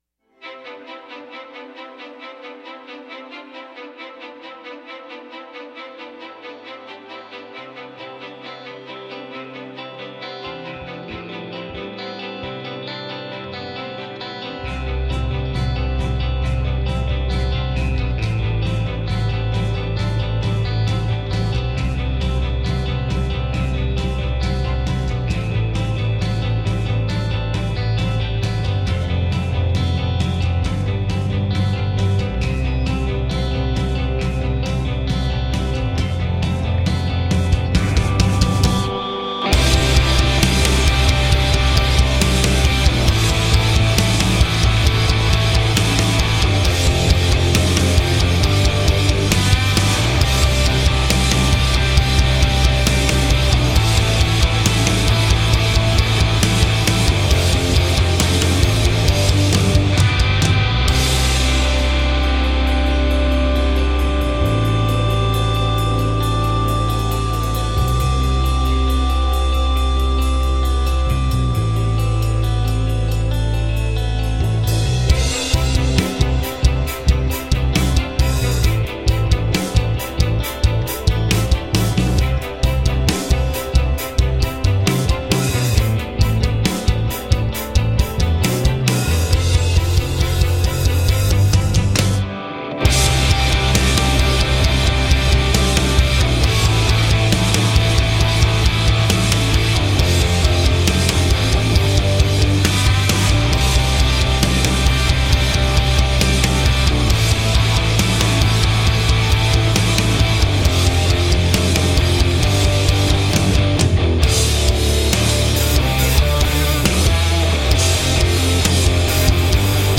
Tagged as: Hard Rock, Other, Instrumental